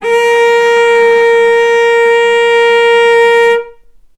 vc-A#4-ff.AIF